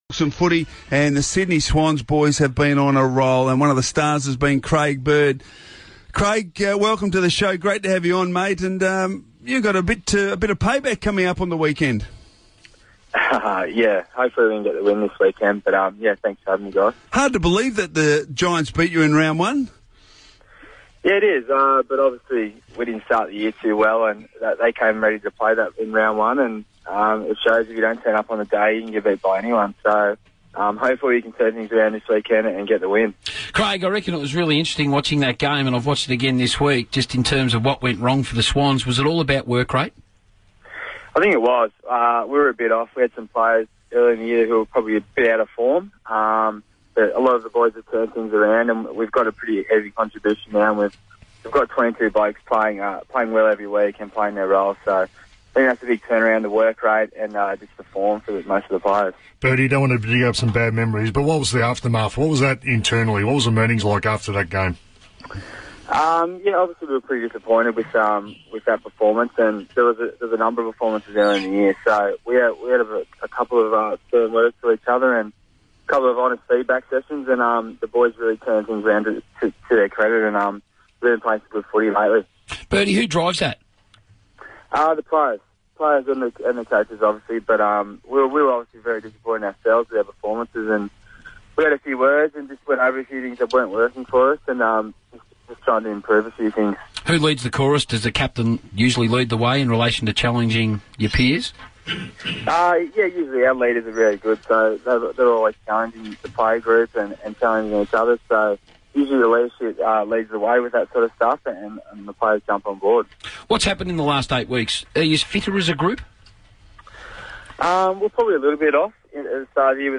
Swans midfielder Craig Bird spoke to RSN Racing & Sport's breakfast program on Friday June 27, 2014